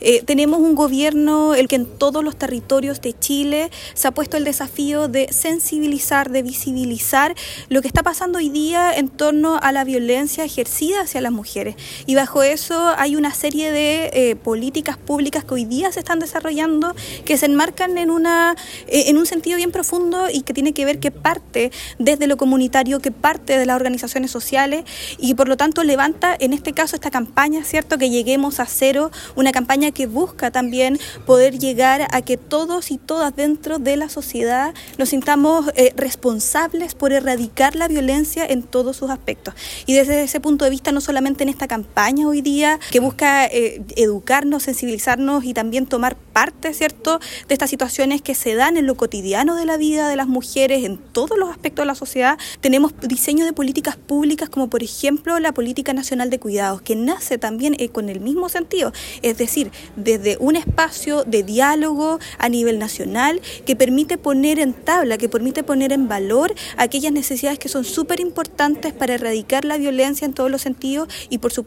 La actividad se realizó en la Sala de Sesiones del Municipio y contó con la participación de autoridades regionales, provinciales y locales; además de dirigentas sociales.
Por su parte la Seremi de Gobierno, Danitza Ortiz, destacó el trabajo que realizan los distintos organismos públicos, que han realizado una labor conjunta con las organizaciones territoriales para levantar estas campañas.